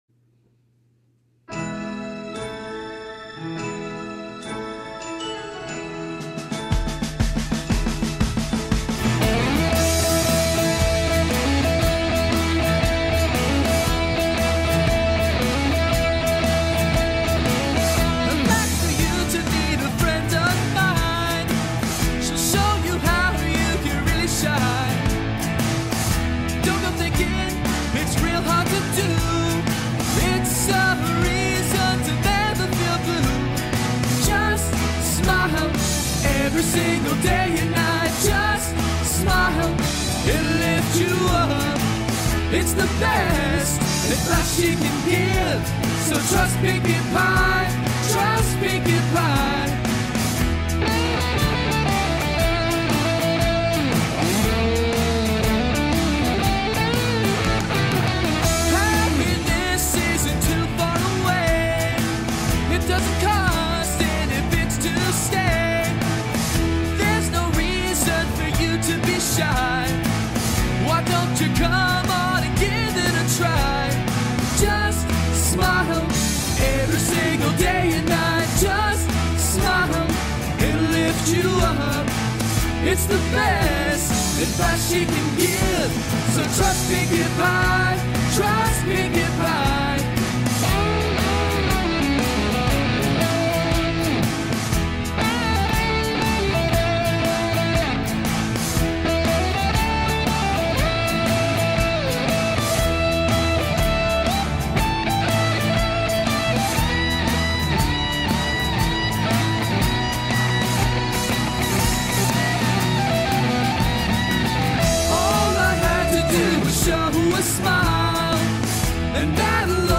This one, though, is my first ever parody song.